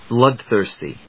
音節blóod・thìrsty 発音記号・読み方
/ˈblʌˌdθɝsti(米国英語), ˈblʌˌdθɜ:sti:(英国英語)/